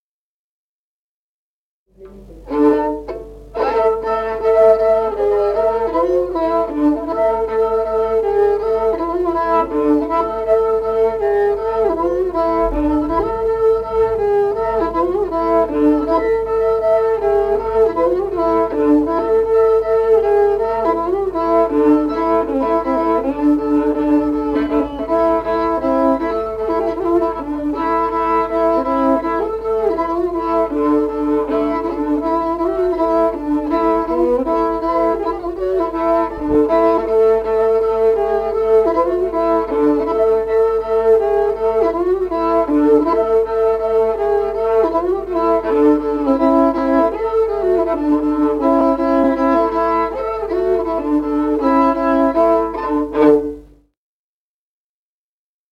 Музыкальный фольклор села Мишковка «Метелица», партия 2-й скрипки.